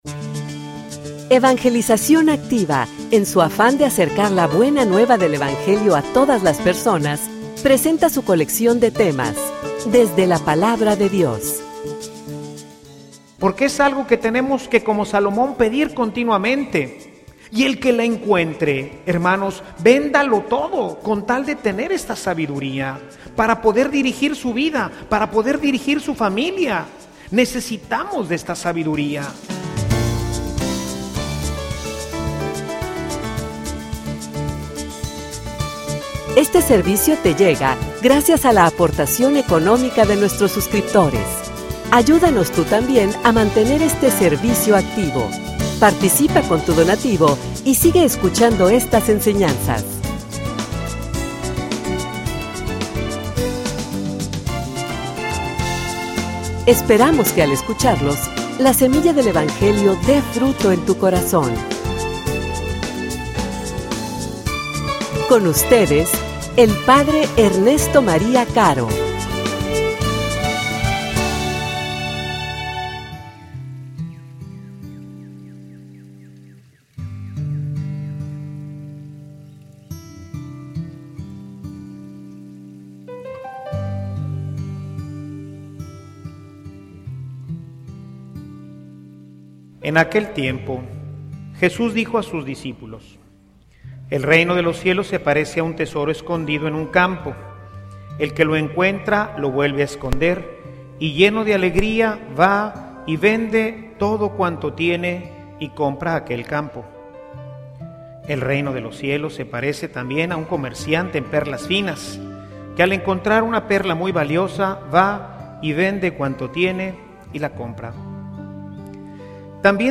homilia_Tu_que_le_pedirias_a_Dios.mp3